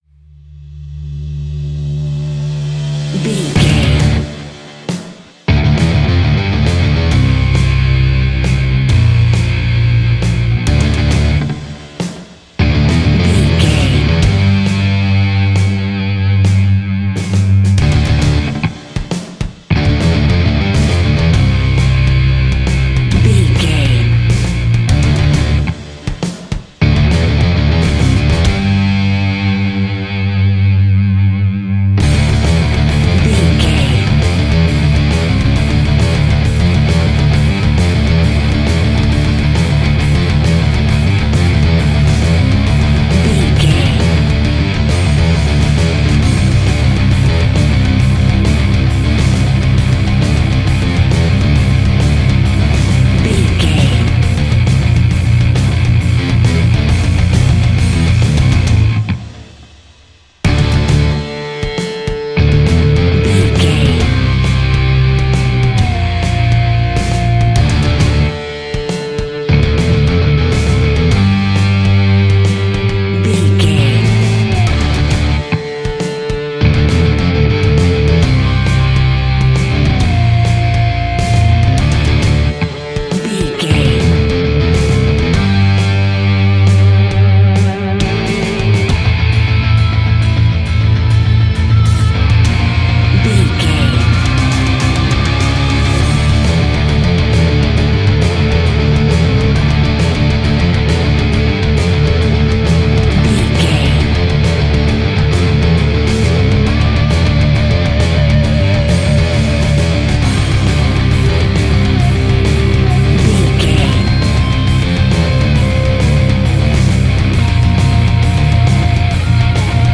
Fast paced
Aeolian/Minor
driving
bass guitar
electric guitar
drums